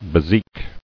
[be·zique]